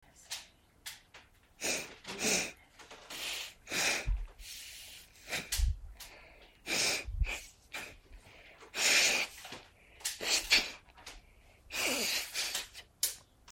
Selection Of Weird Noises Sound Button - Free Download & Play